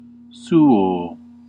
Ääntäminen
Ääntäminen Classical: IPA: /ˈsu.oː/ Haettu sana löytyi näillä lähdekielillä: latina Käännöksiä ei löytynyt valitulle kohdekielelle.